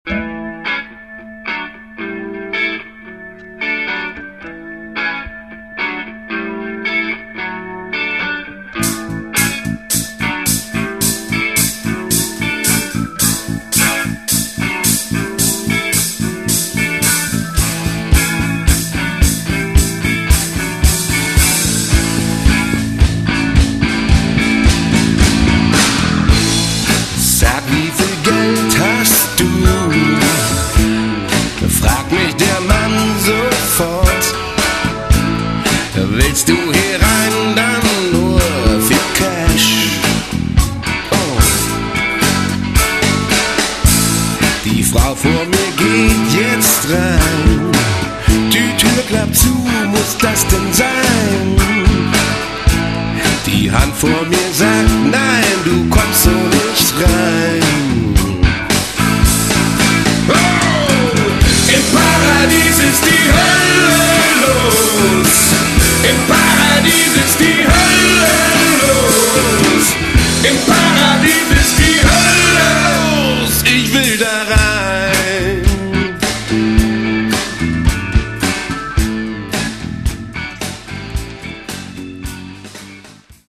Git.und Voc.
Dr., Chor, Bongolein, klappern & rascheln
aufgenommen, gemischt und produziert